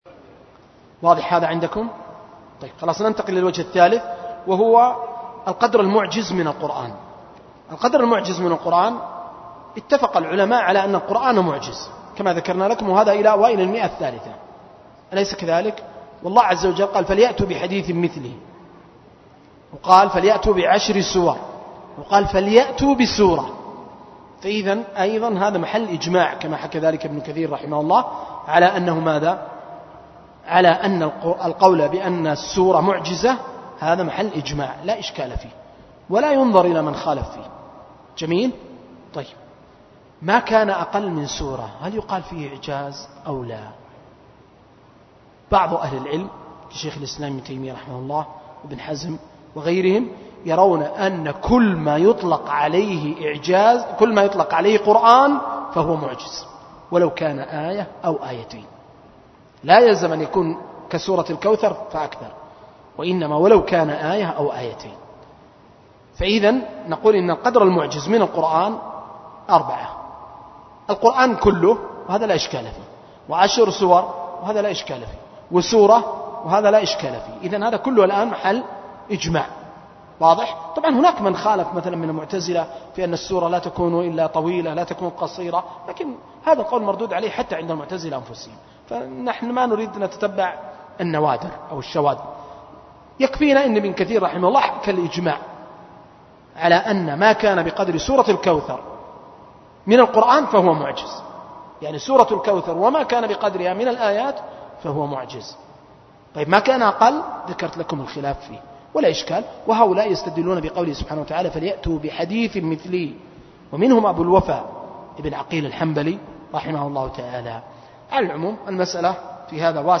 جامع الراجحي – بريدة – السعودية